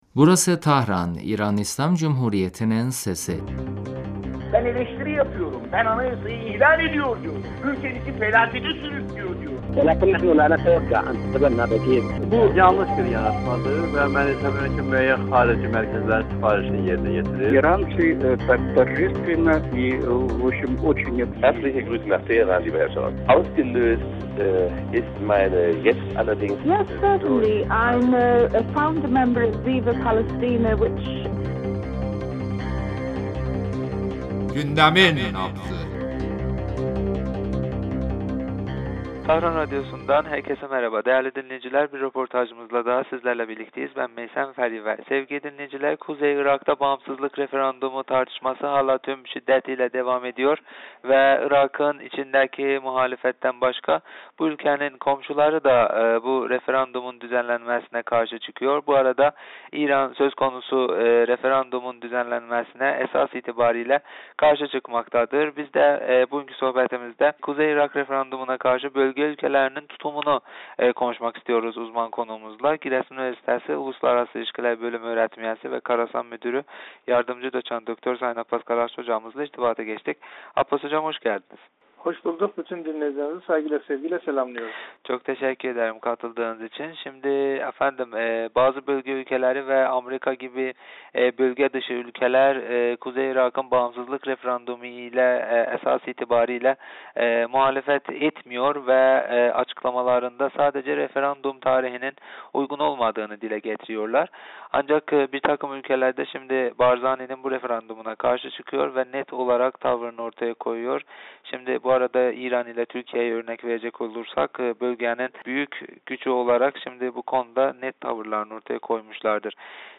telefon görüşmesinde